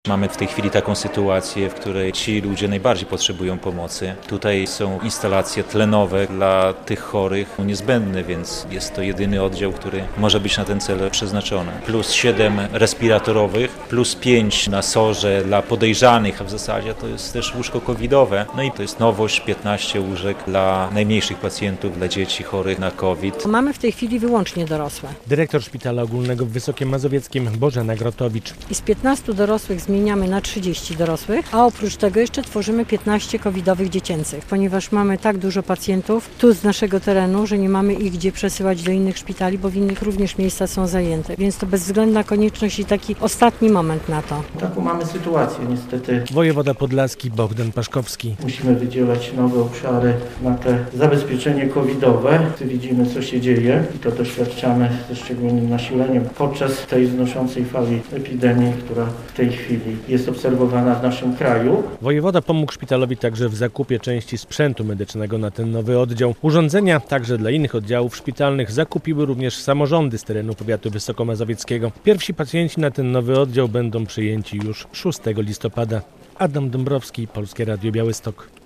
W Wysokiem Mazowieckiem otwarto oddział dla chorych na COVID-19 - relacja